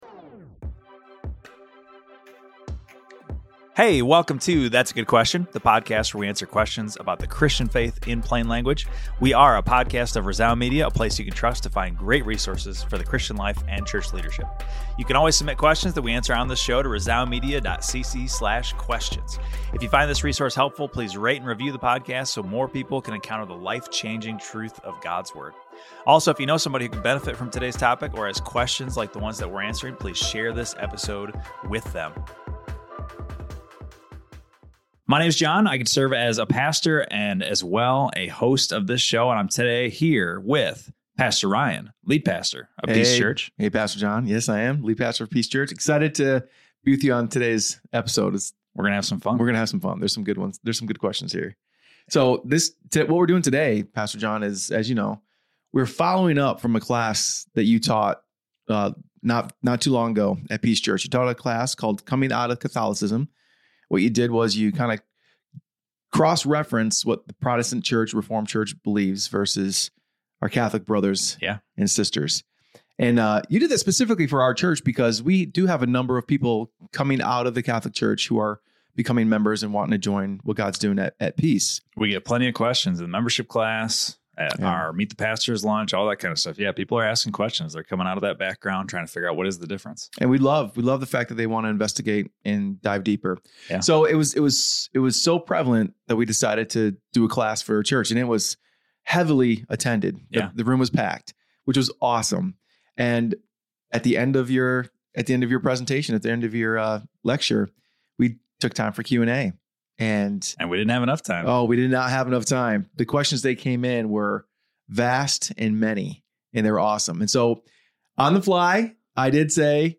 Join us for a thoughtful, engaging conversation that challenges assumptions, explores history, and seeks truth with clarity and grace.